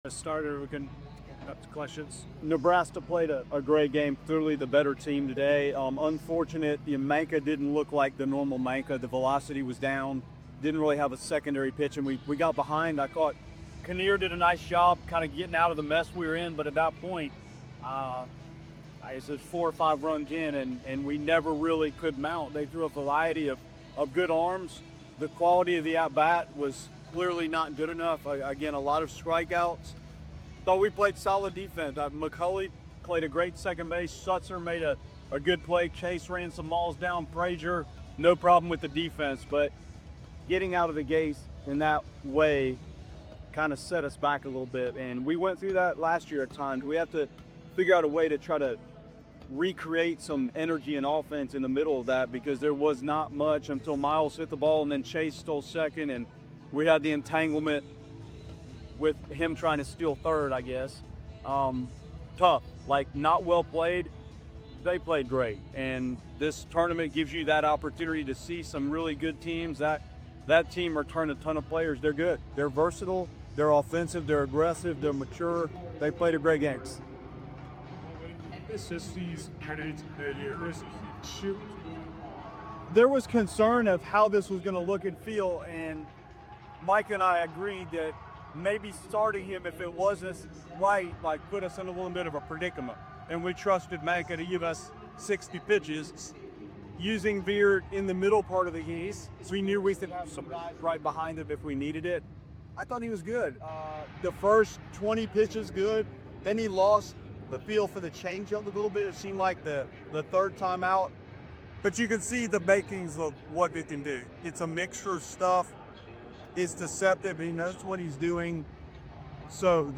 FSU came to Arlington undefeated and left after a disappointing performance, sustaining back-to-back losses. The most recent was a dismantling at the hands of Nebraska. Hear what Link Jarrett has to say in his post-game comments.